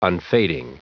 Prononciation du mot unfading en anglais (fichier audio)